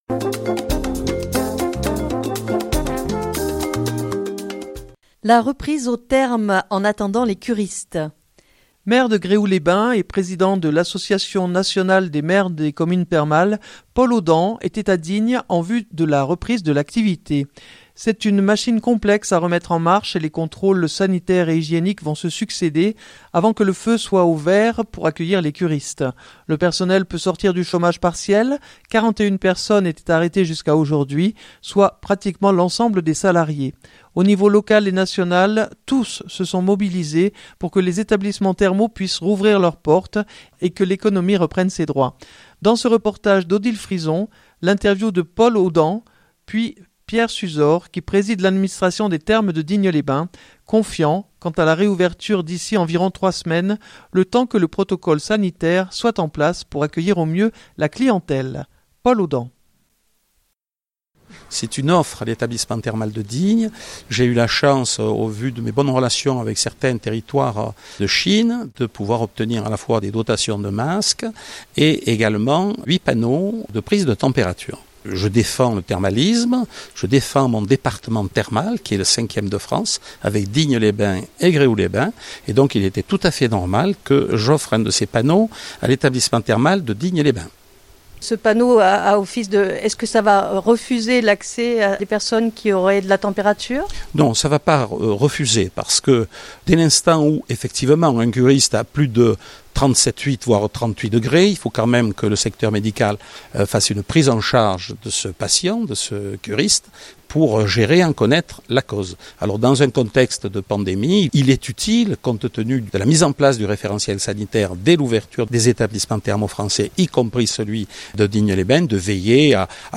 Dans ce reportage